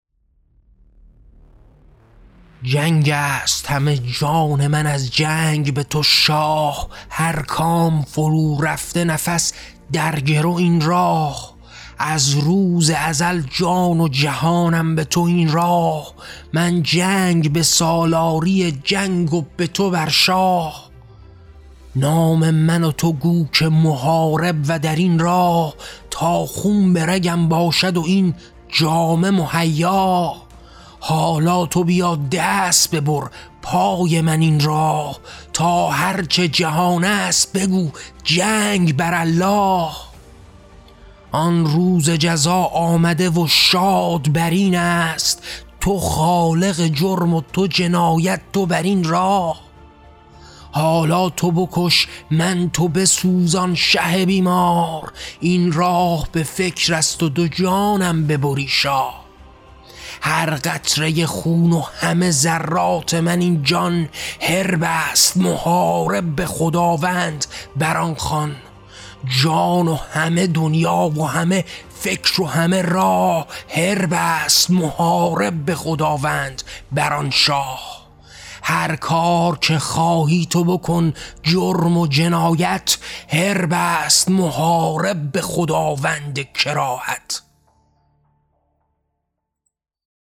کتاب طغیان؛ شعرهای صوتی؛ محاربه: ایستادگی جان در برابر استبداد و شاه